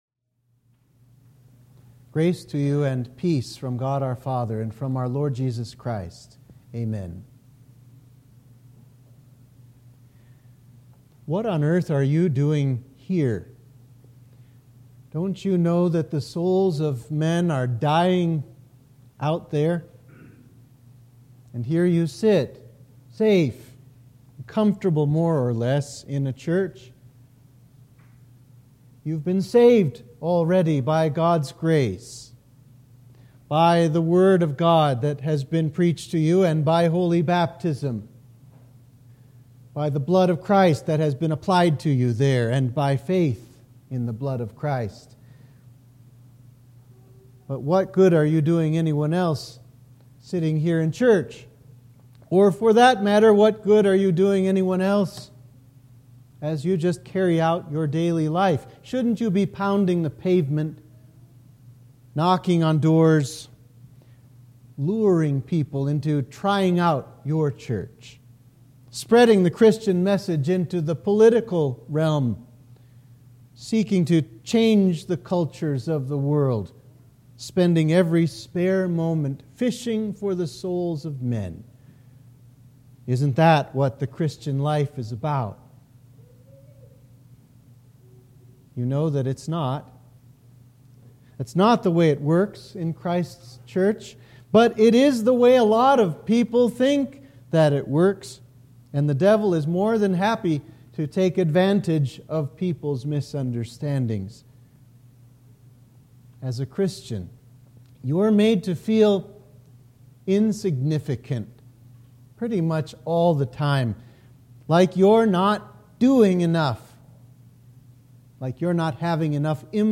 Sermon for the Fifth Sunday after Trinity